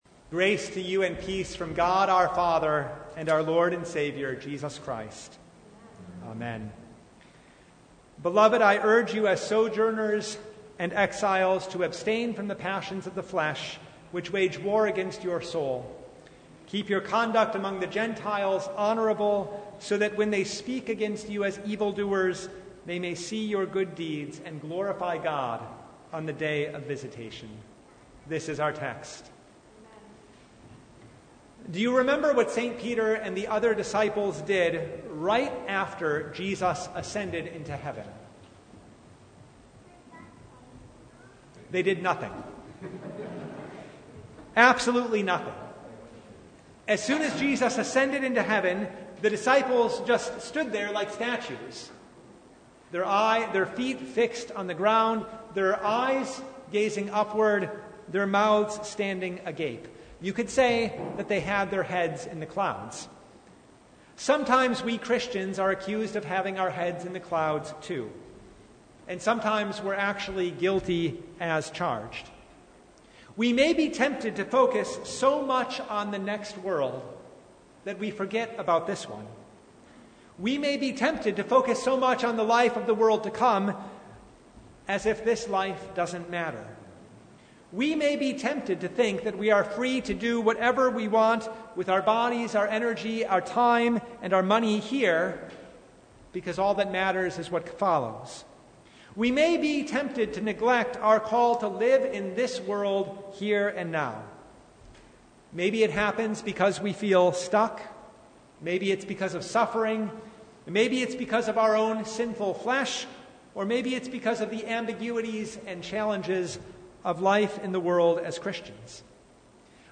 Service Type: Lent Midweek Noon
Sermon Only